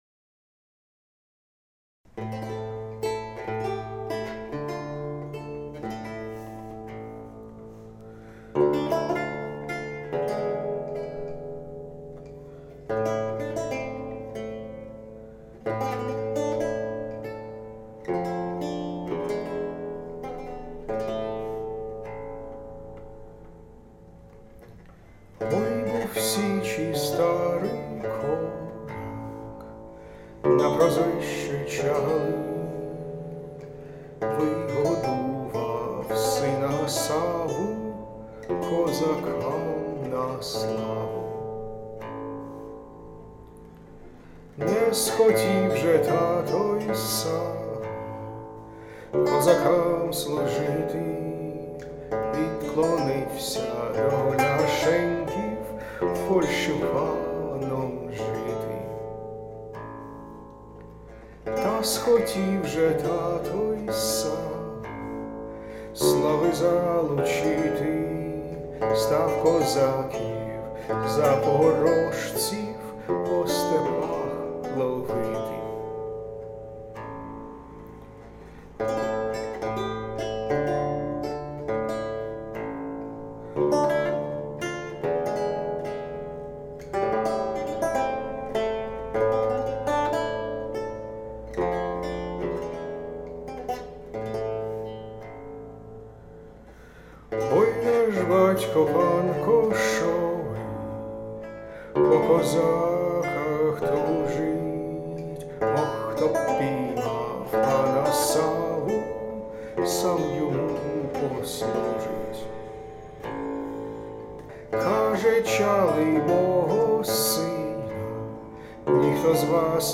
A historical song from ca. 1750-70: The Ballad of Sava Chaly